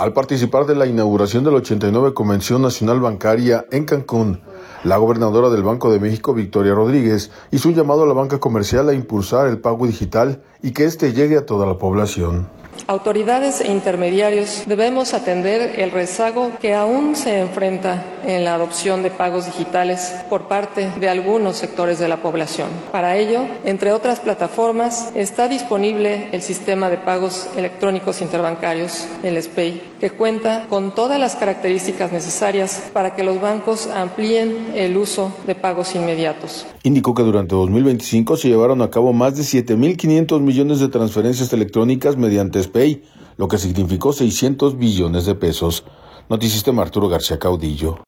Al participar de la inauguración de la 89 Convención Nacional Bancaria, en Cancún, la gobernadora del Banco de México, Victoria Rodríguez, hizo un llamado a la banca comercial a impulsar el pago digital y que este llegue a toda la población.